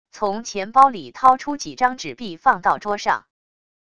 从钱包里掏出几张纸币放到桌上wav音频